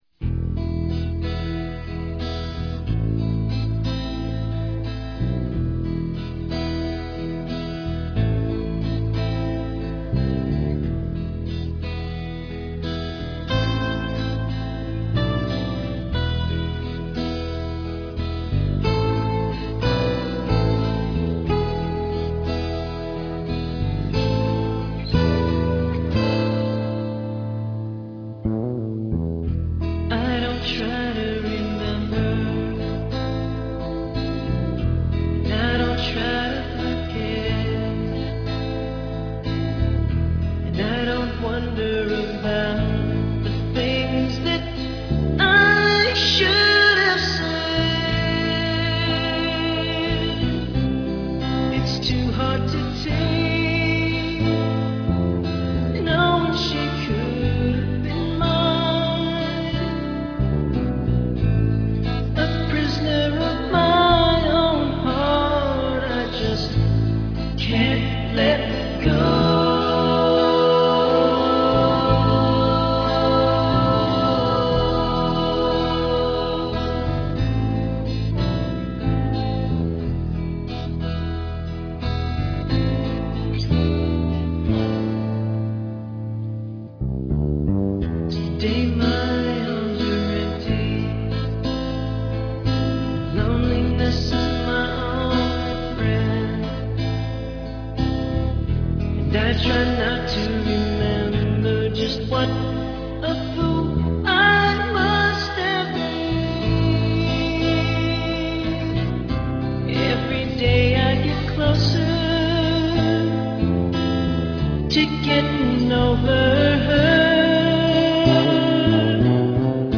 At play in the studio